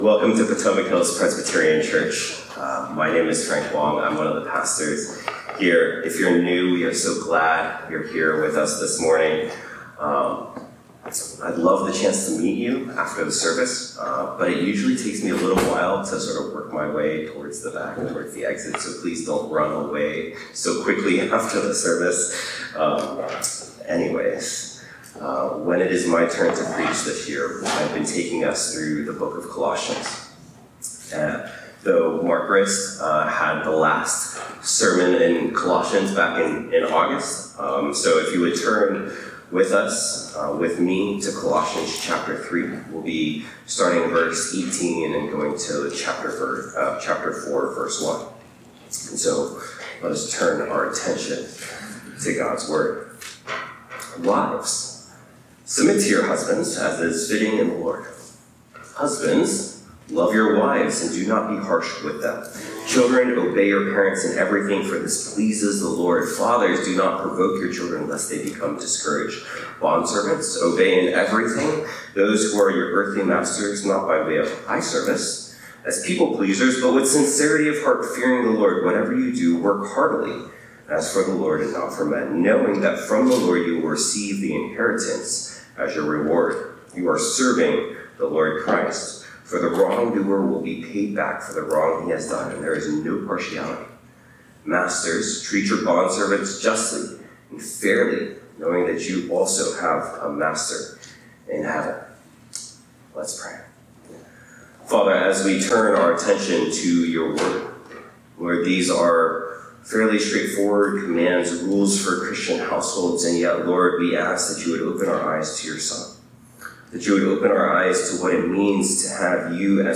phpc-worship-service-9-14-25.mp3